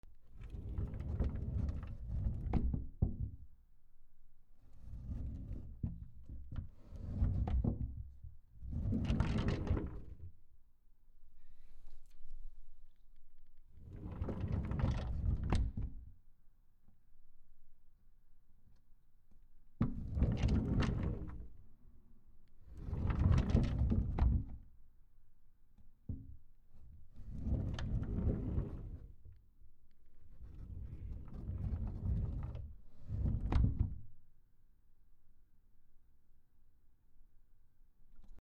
/ K｜フォーリー(開閉) / K05 ｜ドア(扉)
引き戸